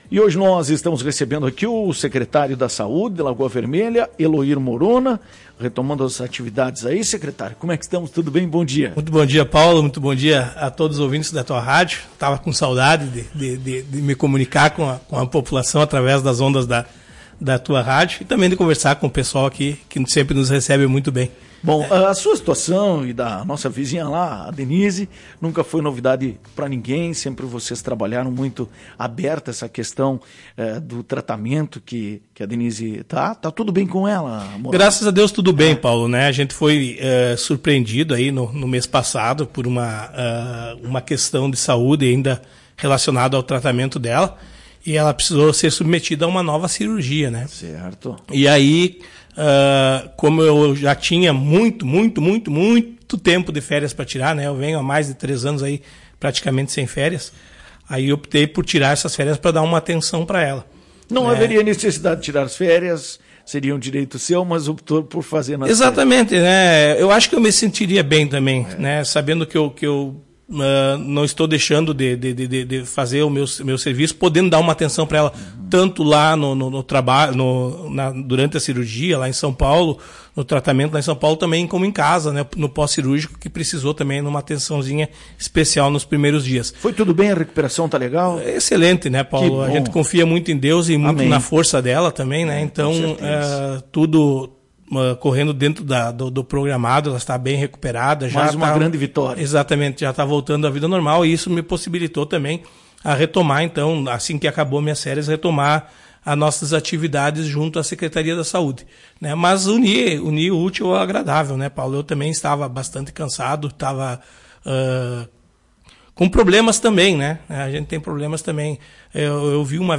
O secretário municipal da saúde de Lagoa Vermelha – Eloir Morona esteve afastado da pasta por um período de férias, em entrevista a Tua Rádio Cacique o mesmo falou sobre o seu retorno e a grande demanda de trabalhos.
Outro assunto que foi abordado durante a entrevista foi sobre a varíola do macaco, tendo em vista que a OMS já decretou emergência global. Ouça a entrevista.